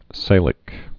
(sālĭk, sălĭk) also Sa·lique (sālĭk, sălĭk, să-lēk)